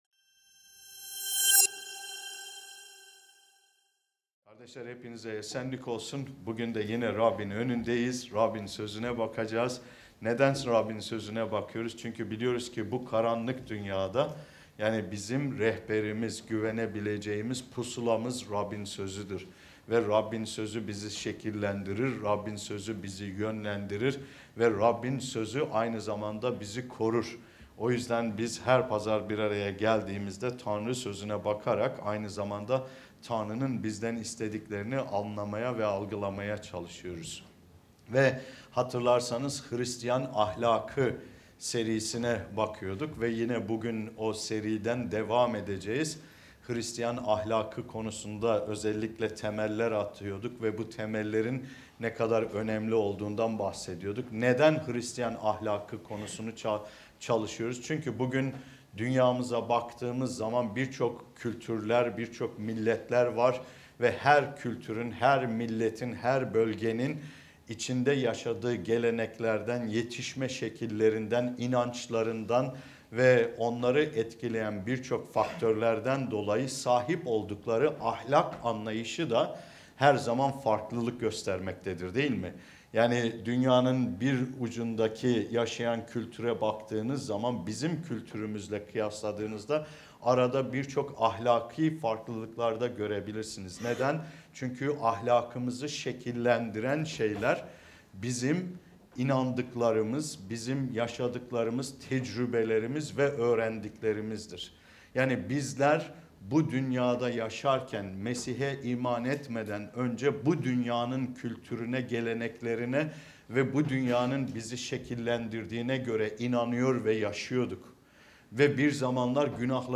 (Hristiyan Ahlakı Vaaz Serisi 6 ) Hristiyan Ahlakında Sorumluluk Almak ve Önemi